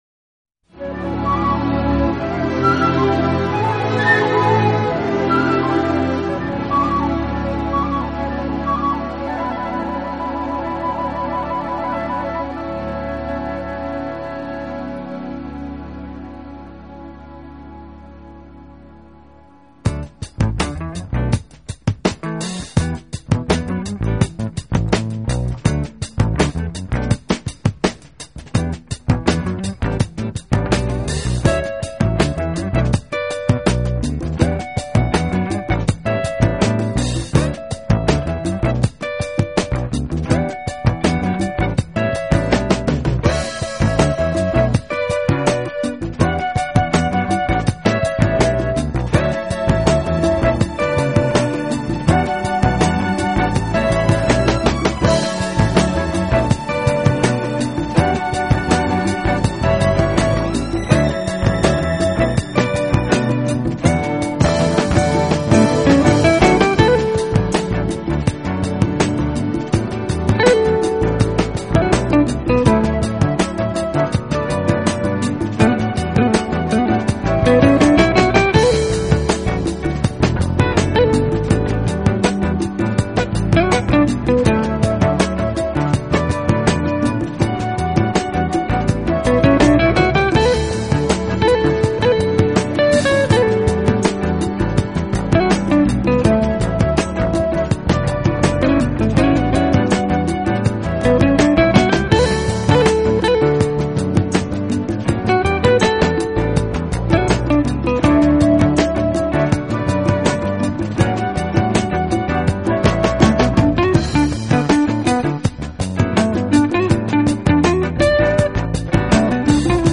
专辑中6首歌曲中有5首是器乐曲，而